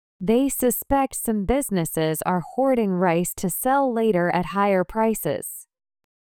１文ずつ区切ったスロー音声を再生し、文字を見ずにリピートしましょう。